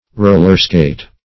Rollerskate \Roll"er*skate\ to glide on roller skates, as one might on ice skates.